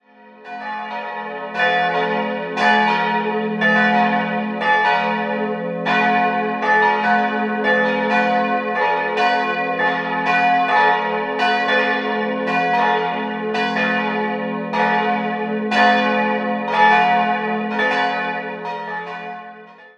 Die Konsekration fand 1770 statt. 3-stimmiges Te-Deum-Geläut: fis'-a'-h' Die beiden größeren Glocken wurden 1950 von der Gießerei Czudnochowsky gegossen, die kleine ist ein Werk von Mathias Stapf (Eichstätt) aus dem Jahr 1786.